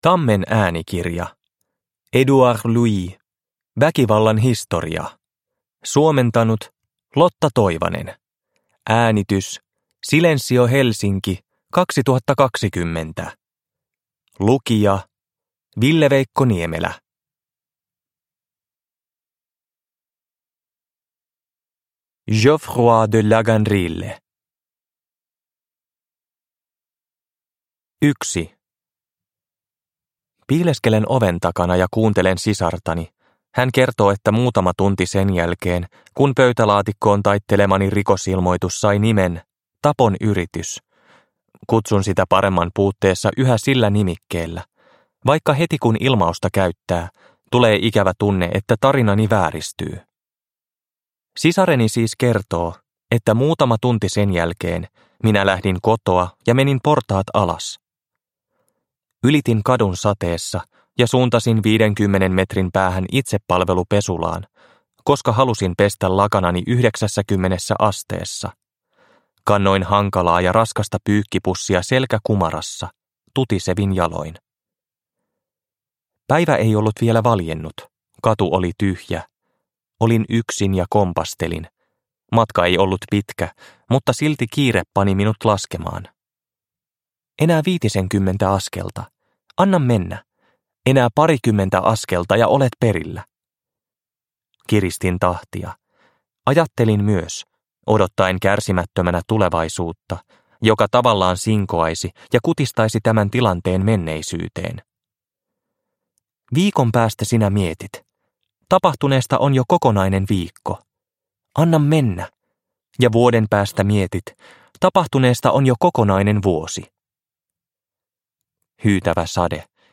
Väkivallan historia – Ljudbok – Laddas ner